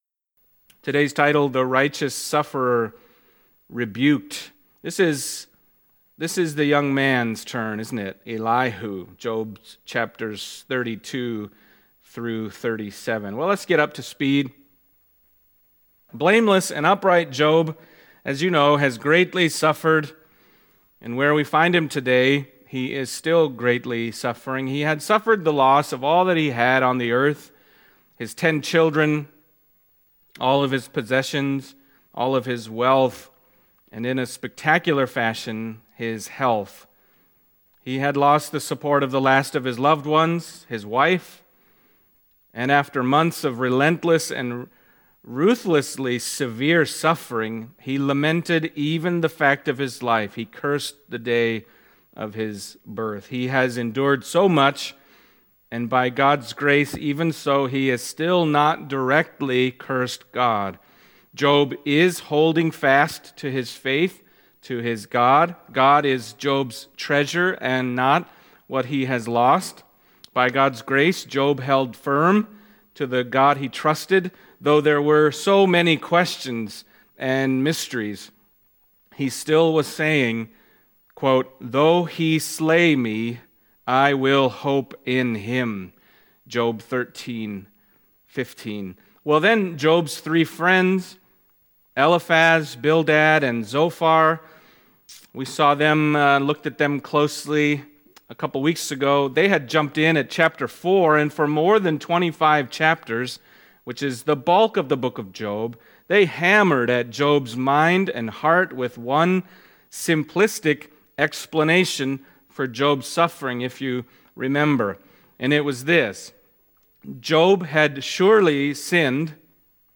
Passage: Job 32:1-37:24 Service Type: Sunday Morning